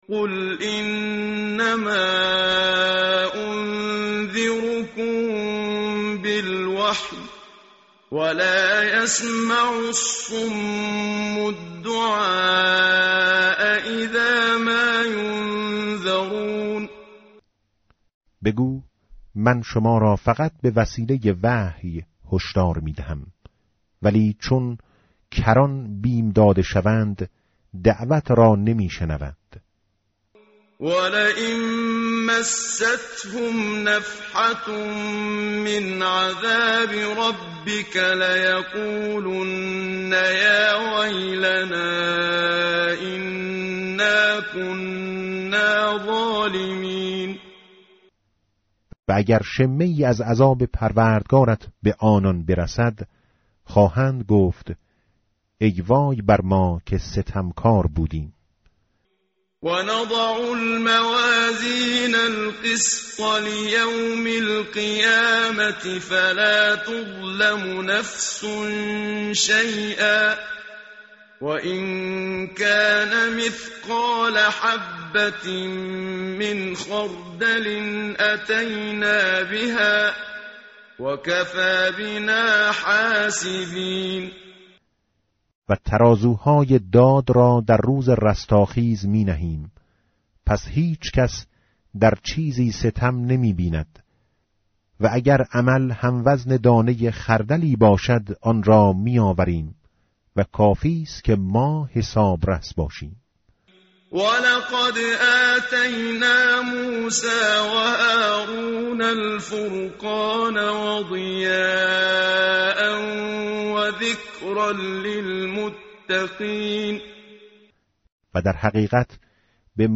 متن قرآن همراه باتلاوت قرآن و ترجمه
tartil_menshavi va tarjome_Page_326.mp3